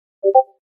Download Free Discord Message Ping Sound Effects
Discord Message Ping